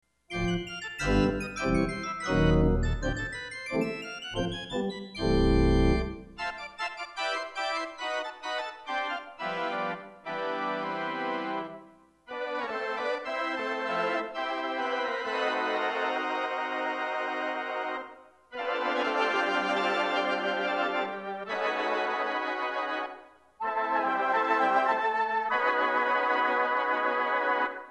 --Strings with Glock--
Viol d'Orchestre 8'
Viol d'Orchestre 4'
Fifteenth 2'
Glockenspiel
At least the Main Tremulant -off-
It's fairly incisive, and works well for the main melody of something like "Melody on the Move".